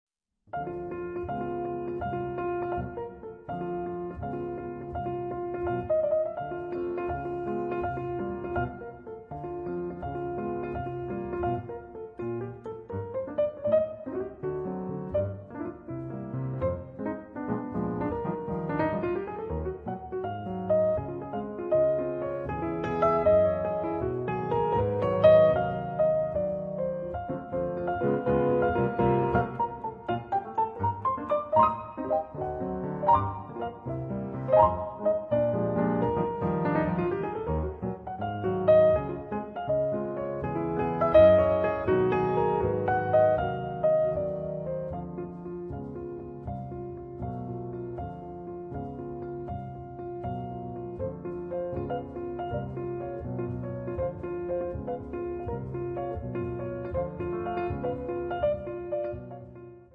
pianoforte
palesa la sua puntualità nei cambi di tempo